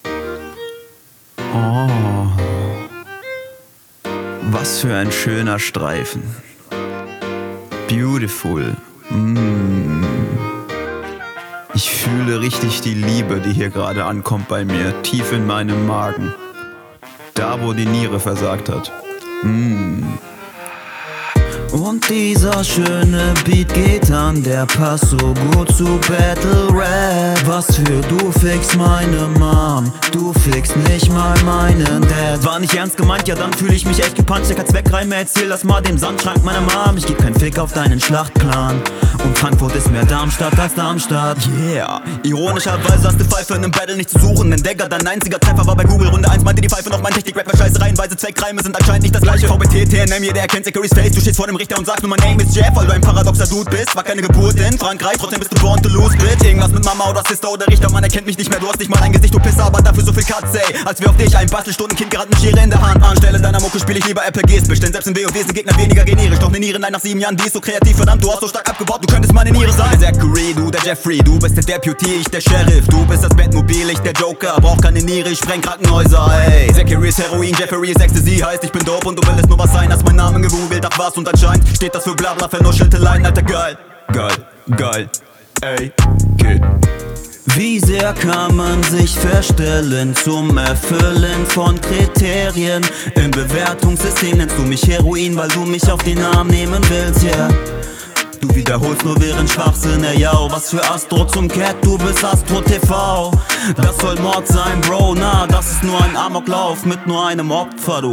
Der Anfang ist trotz Autotune schief.